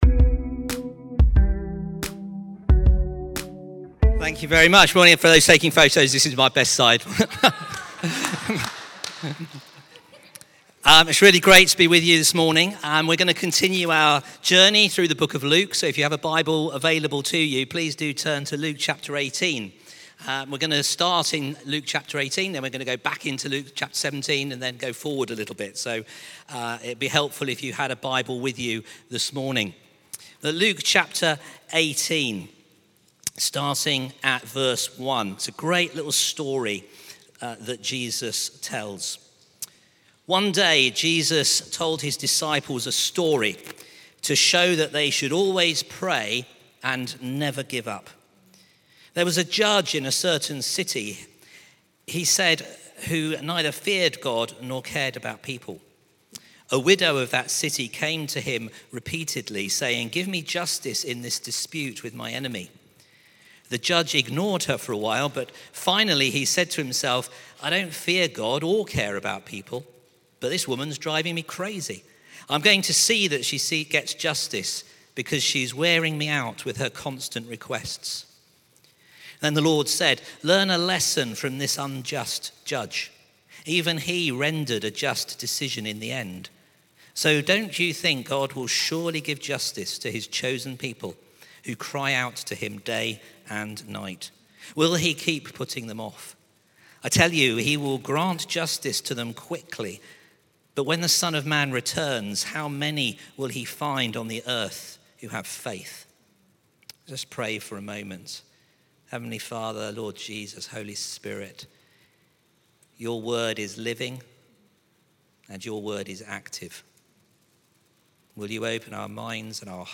Sunday Messages | The Fullness of Christ - Part31